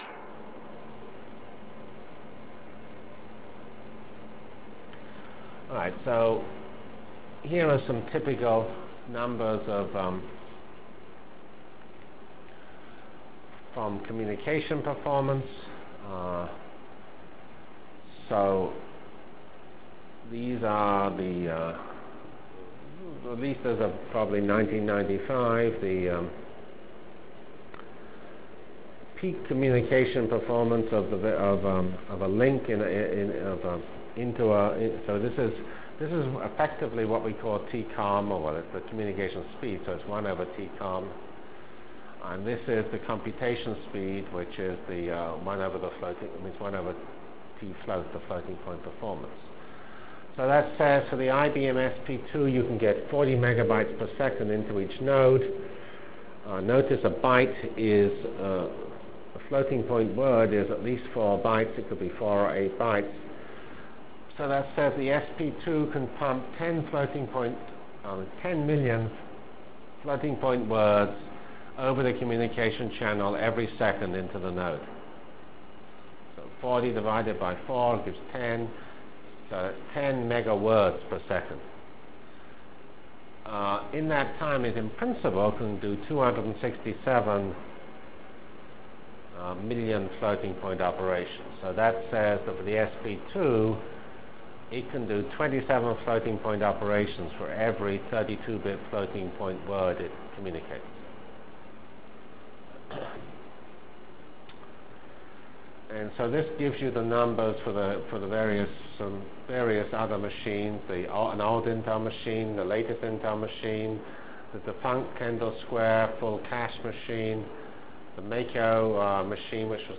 Delivered Lectures of CPS615 Basic Simulation Track for Computational Science -- 12 September 96.